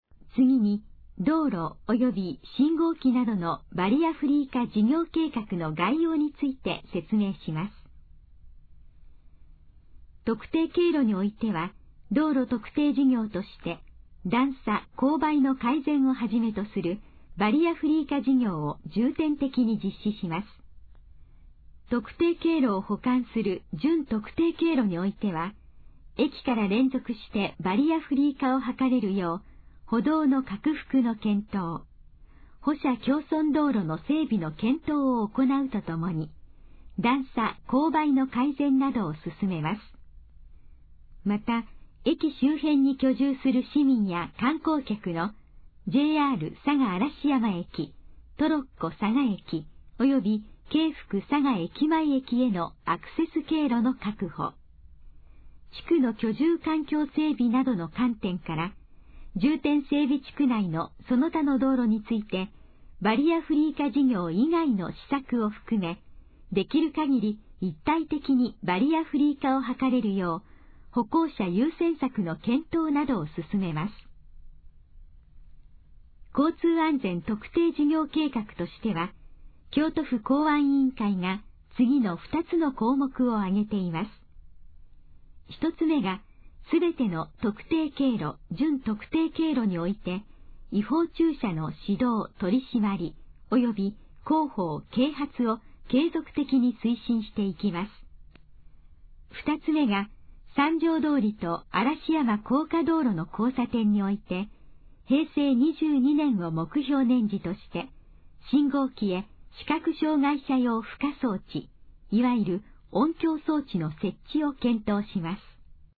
以下の項目の要約を音声で読み上げます。
ナレーション再生 約227KB